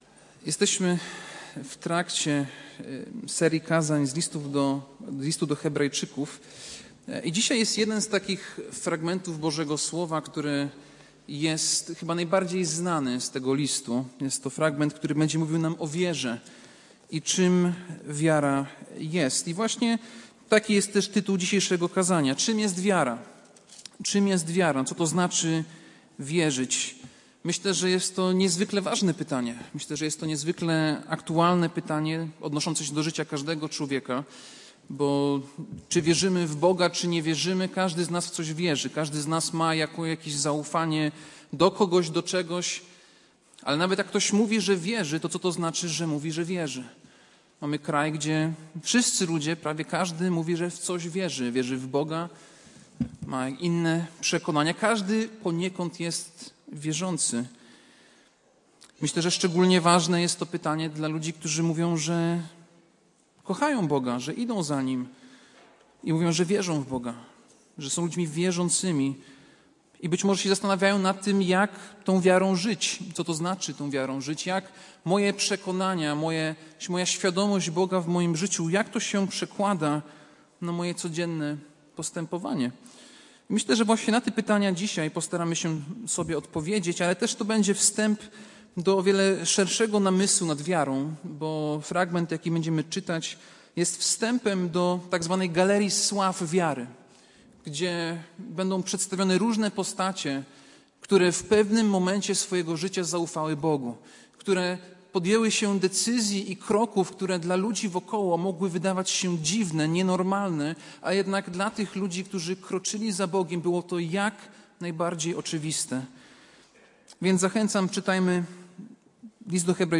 Passage: List do Hebrajczyków 11, 1-7 Kazanie